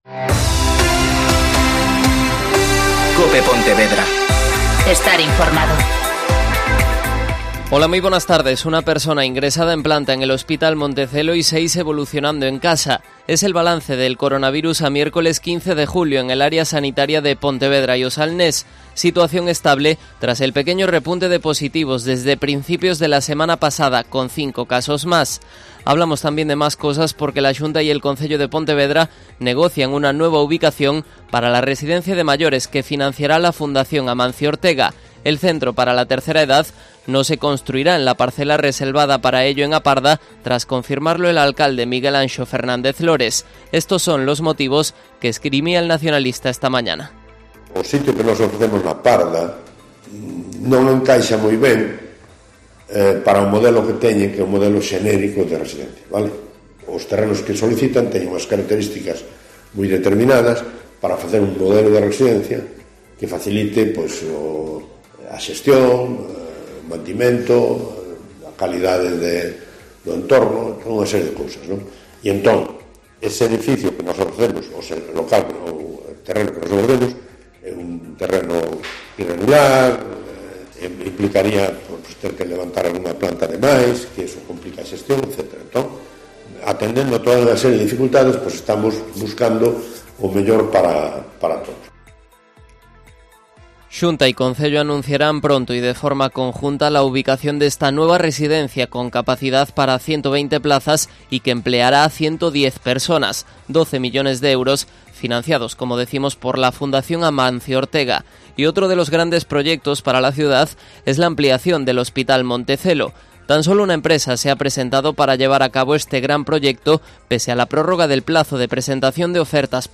Mediodía en COPE Pontevedra (Informativo 14:20h)